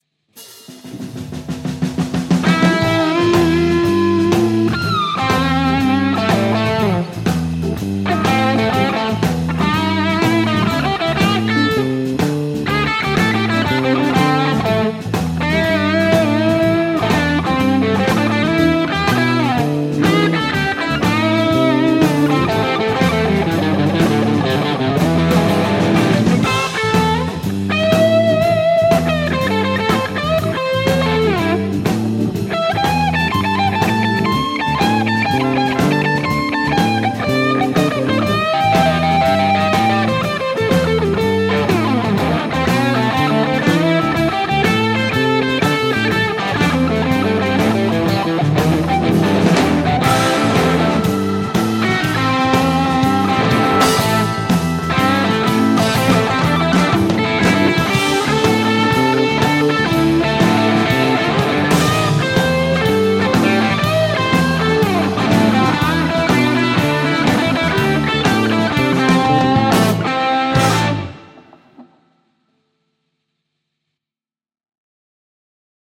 Jos vaikka bluesia:
- Laadi annetun taustan päälle solistinen osuus valitsemallasi instrumentilla